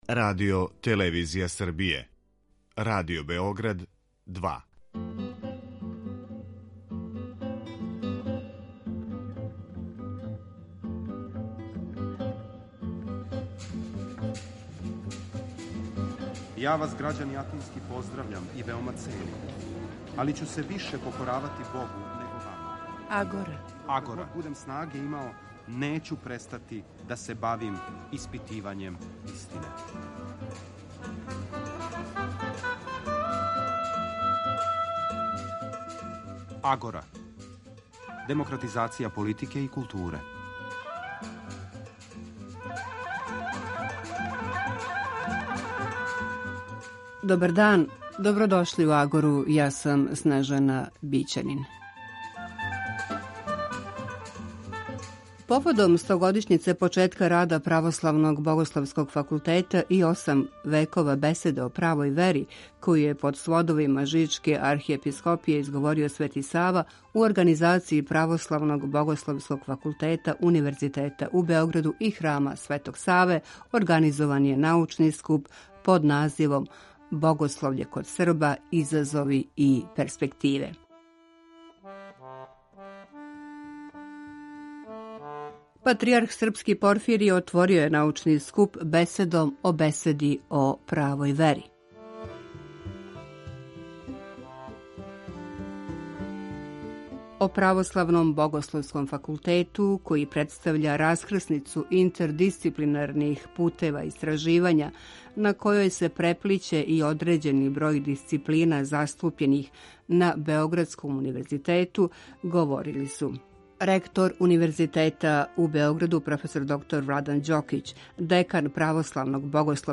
У богатом уметничком програму наступили су хор Школе црквеног појања Свети Јован Дамаскин и хор студената Православног богословског факултета и ученика Богословије Светог Саве у Београду.